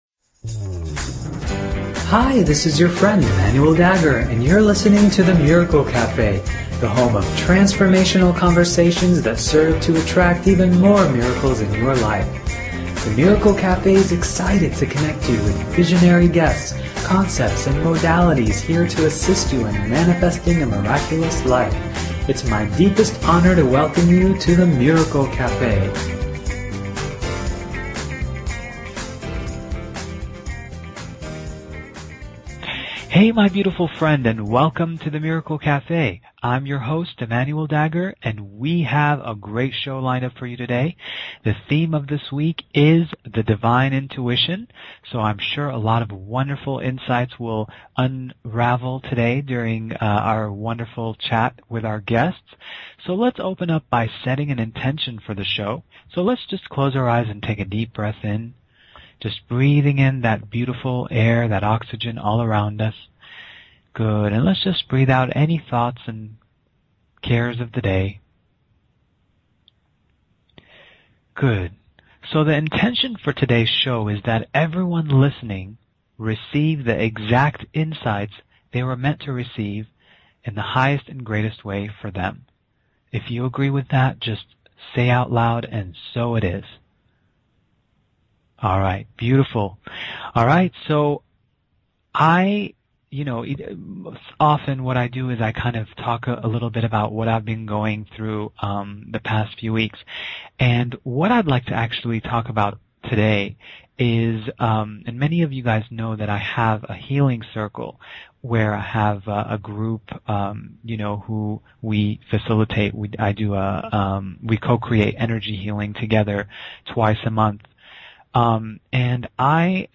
Talk Show Episode, Audio Podcast, The_Miracle_Cafe and Courtesy of BBS Radio on , show guests , about , categorized as